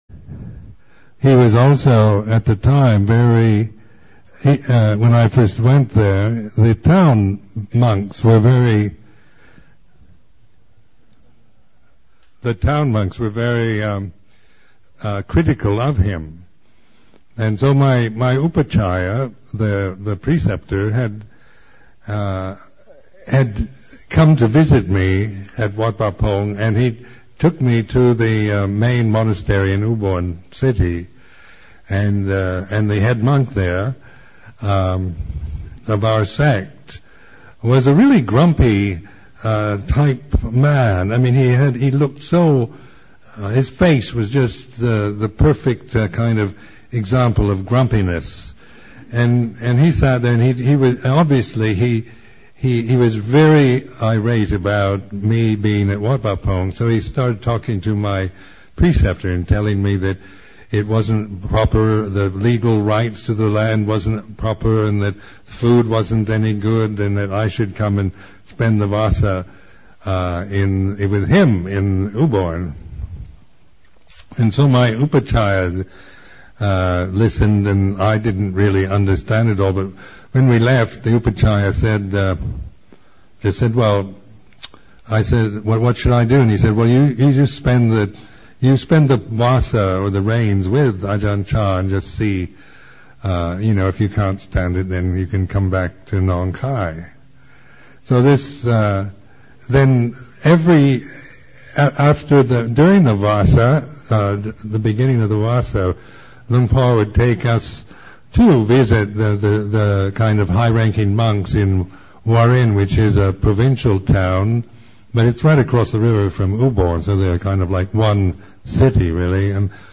Reflection